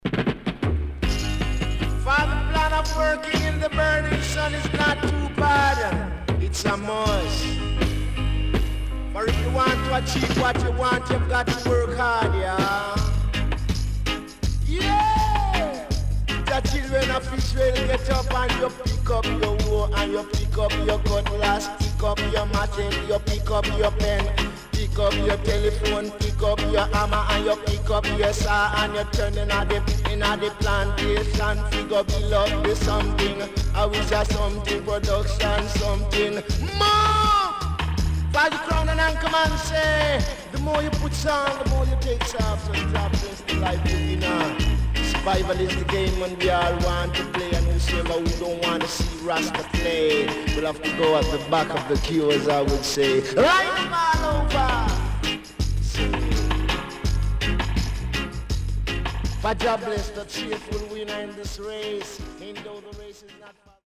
Fine Roots Vocal
SIDE A:少しチリノイズ入りますが良好です。